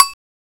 Index of /m8-backup/M8/Samples/Fairlight CMI/IIX/PERCUSN1
SHTBELL.WAV